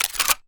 gun_shotgun_pickup_02.wav